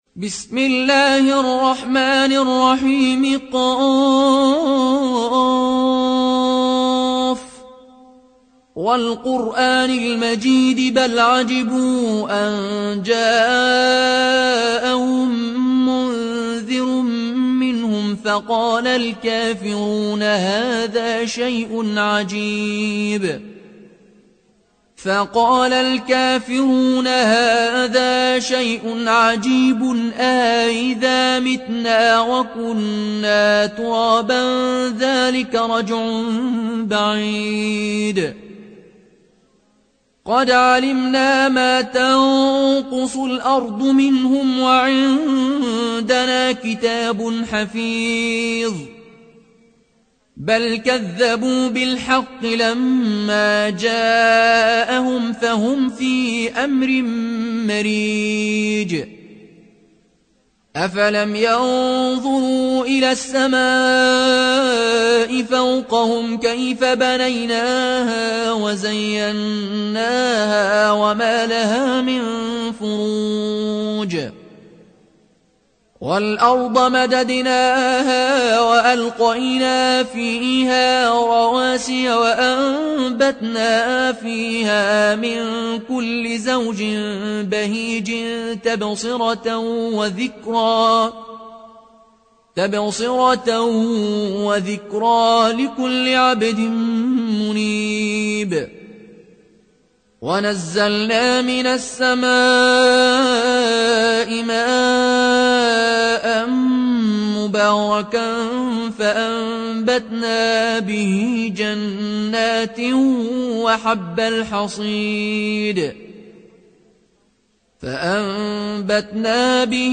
روایت فالون از نافع